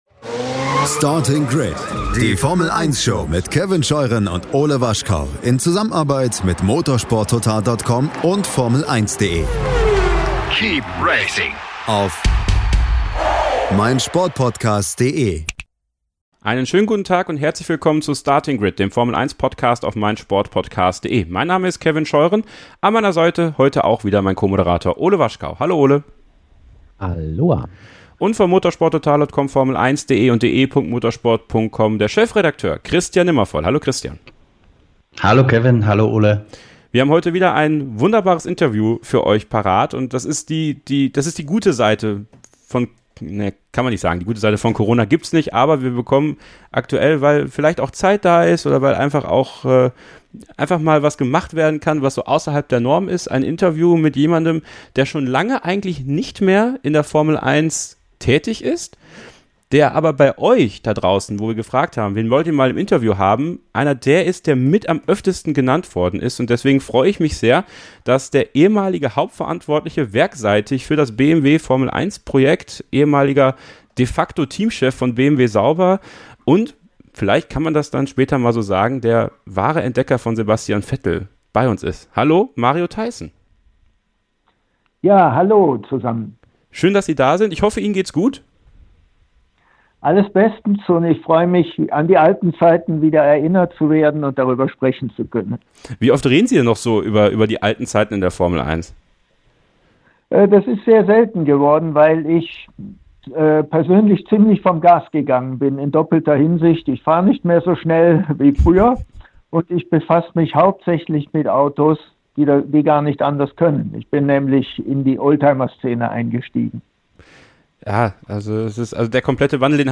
Nach langer Abstinenz aus den Medien gibt es für euch in der neuen Ausgabe ein exklusives und ausführliches Interview mit dem gebürtigen Monschauer.